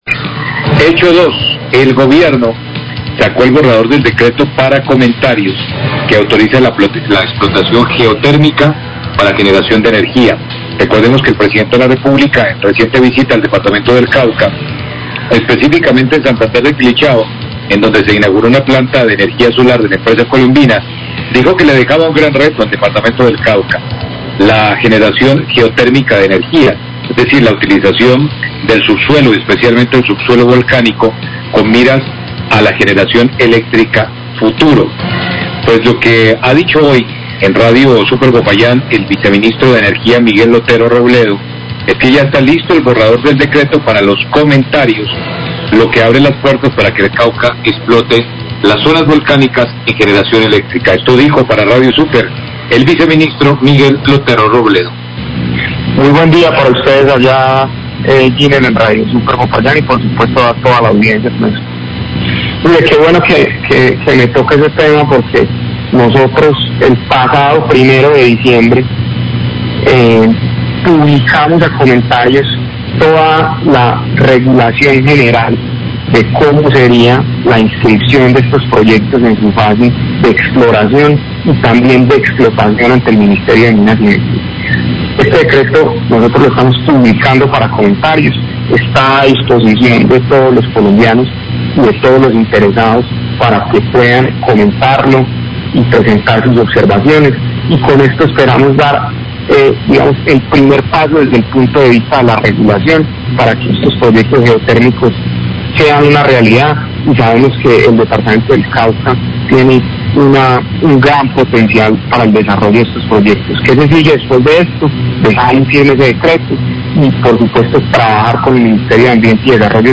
Radio
El Gobierno Nacional sacó el borrador para el decreto para comentarios que autorizan la explotación geotérmica para generación de energía. El proyecto abre las puertas para que el Cauca explote las zonas volcánicas. Declaraciones del Viceministro de Minas y Energía.